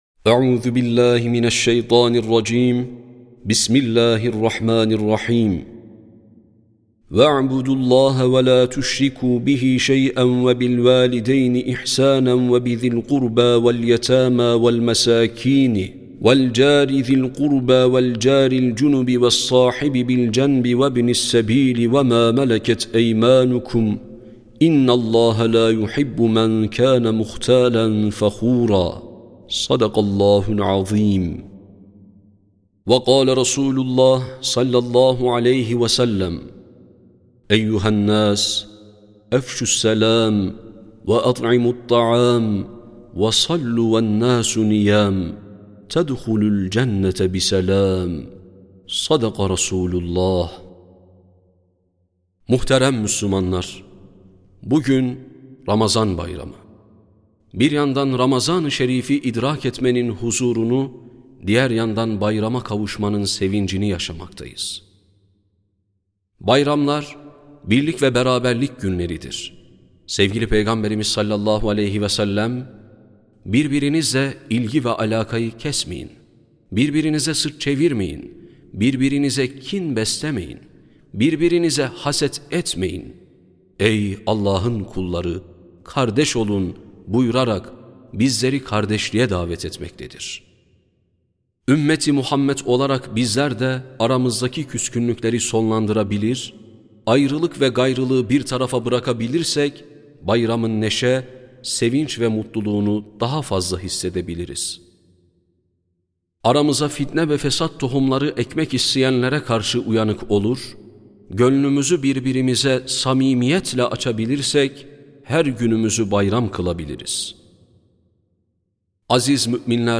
Sesli Hutbe (Ramazan Bayramı).mp3